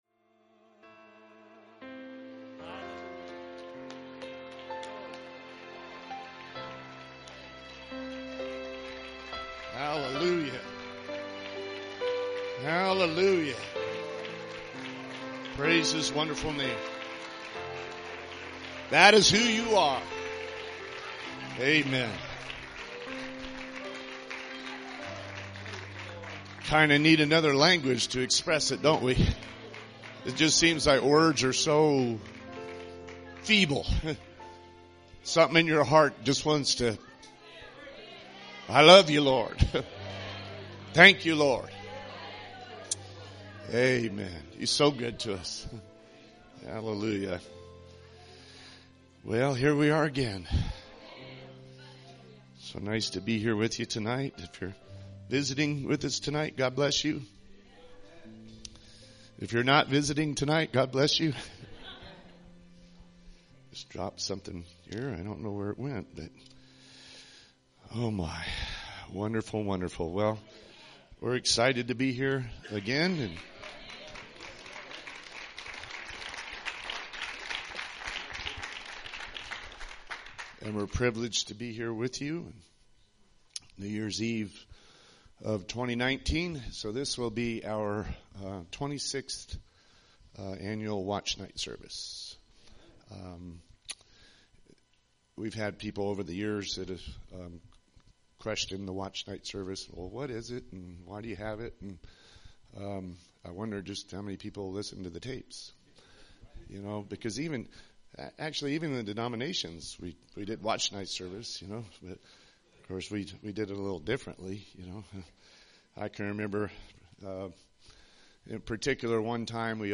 Watchnight Services